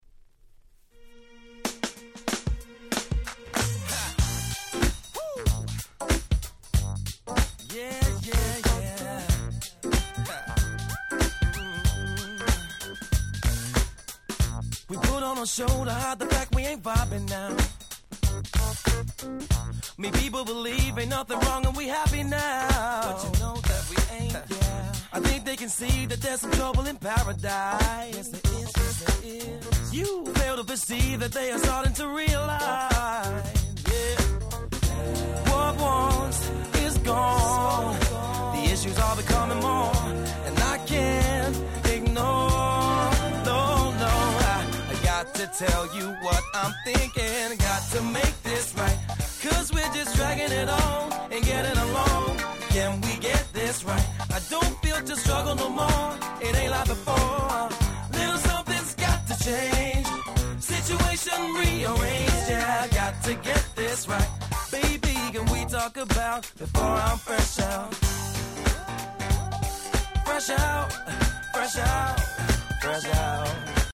05' Super Nice UK R&B/Neo Soul !!